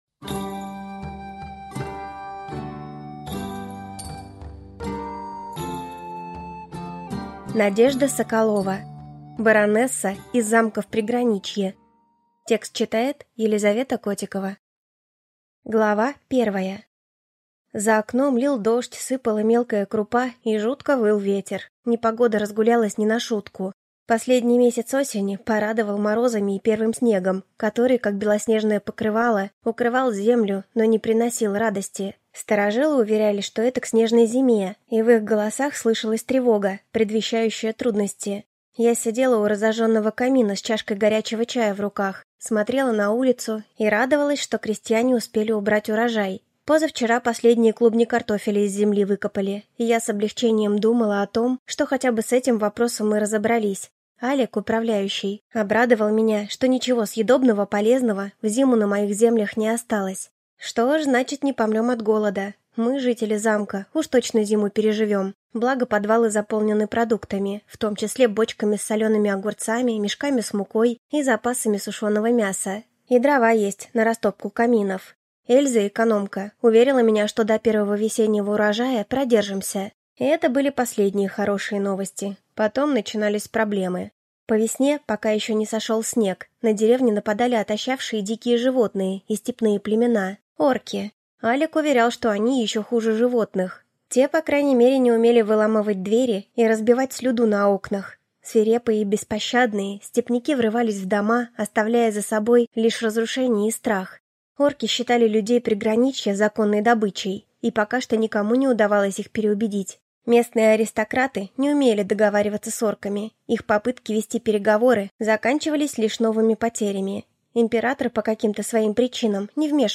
Леди Шпионка (слушать аудиокнигу бесплатно) - автор Виктория Лукьянова